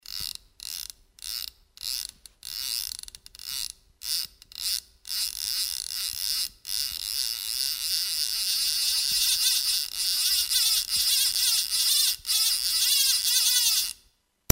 fliegenrolle2.mp3